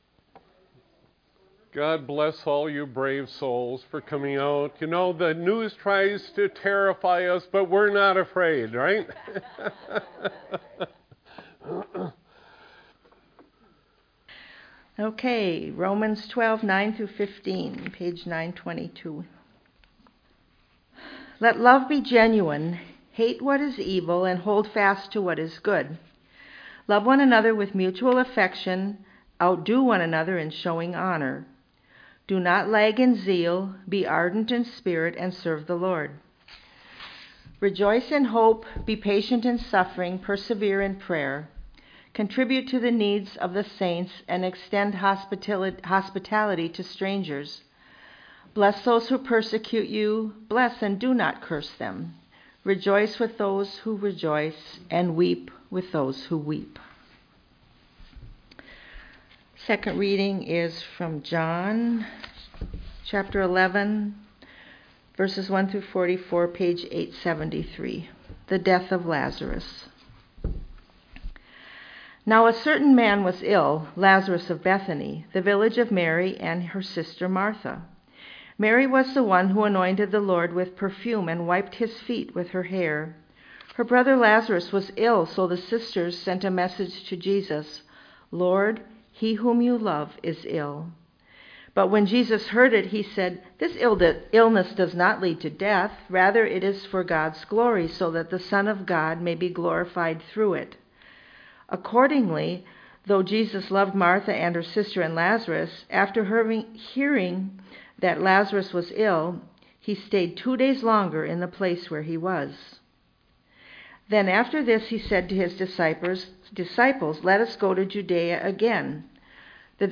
sermon-3.mp3